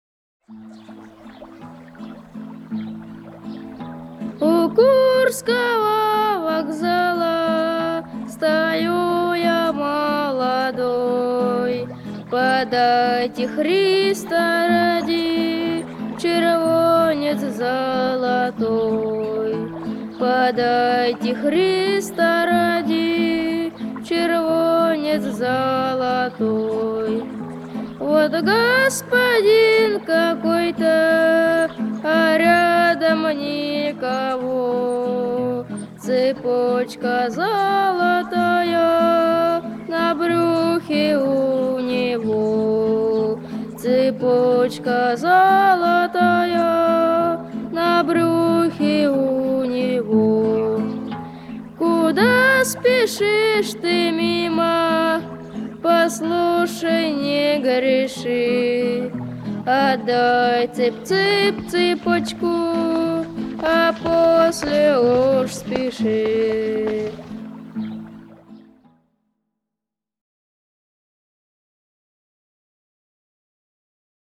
• Качество: 256, Stereo
гитара
печальные